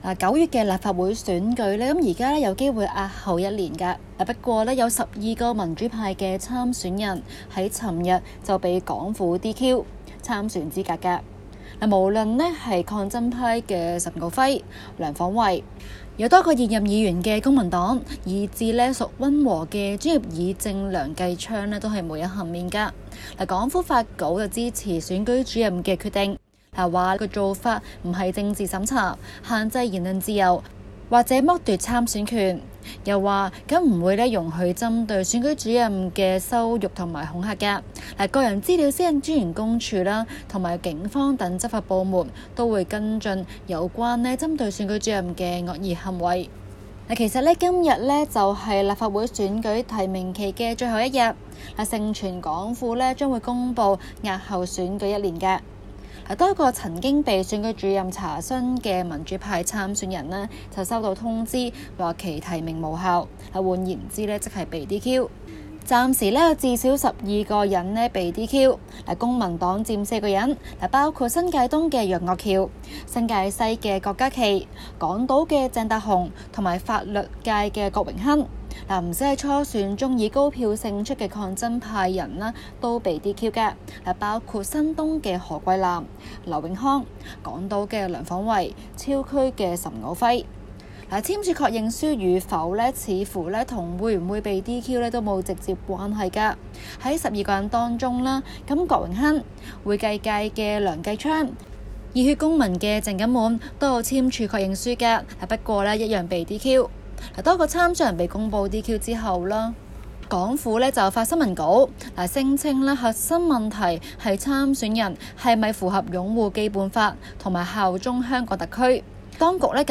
今期【中港快訊 】環節報道港府取消12名民主派人士參選資格，規模之大史無前例。